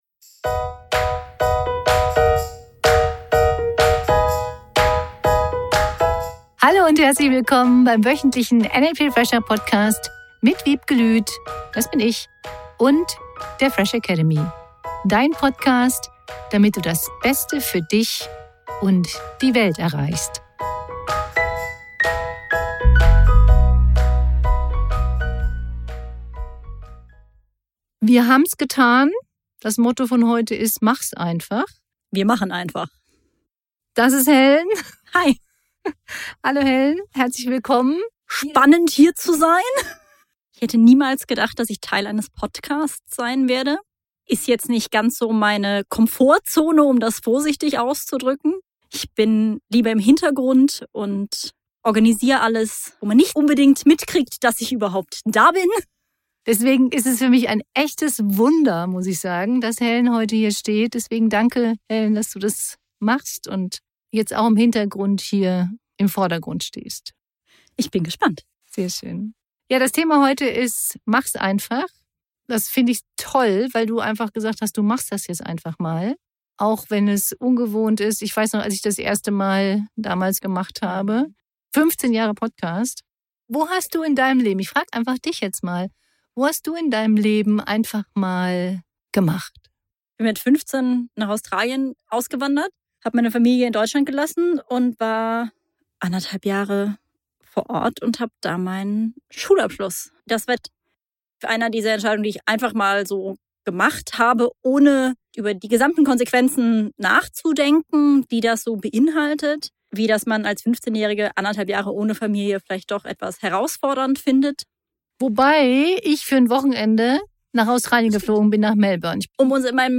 Gemeinsam sprechen sie darüber, warum es sich lohnt, nicht alles zu zerdenken und stattdessen einfach loszulegen.